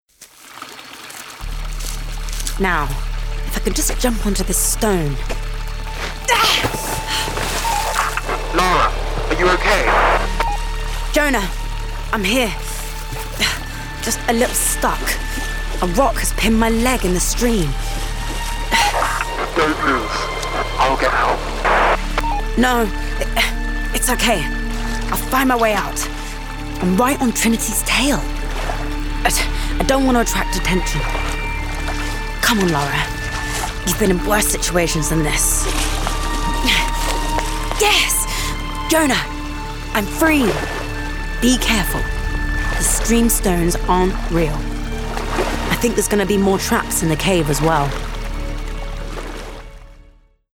RP ('Received Pronunciation')
Acting Drama Audiobook Game Animation